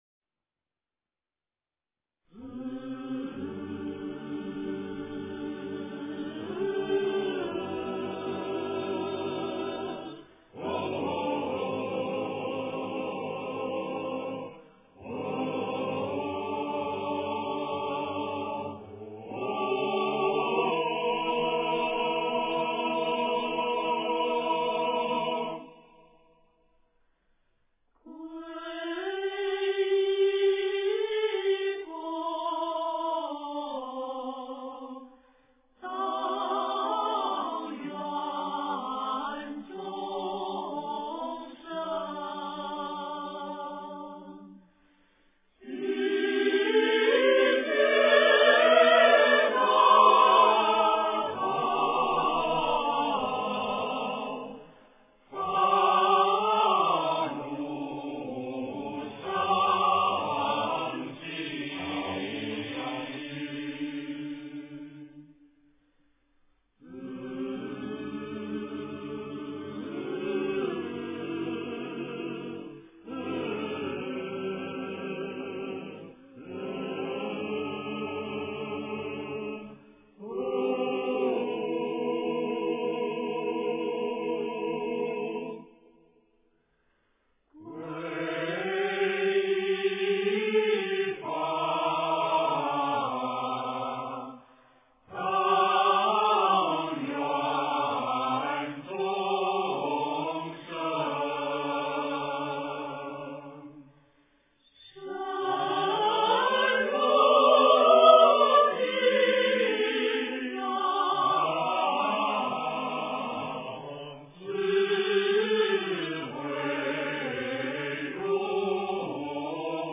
三归依--僧团 经忏 三归依--僧团 点我： 标签: 佛音 经忏 佛教音乐 返回列表 上一篇： 大悲咒+伽蓝赞--僧团 下一篇： 普佛(代晚课)(下)--僧团 相关文章 净土文--佛教唱颂编 净土文--佛教唱颂编...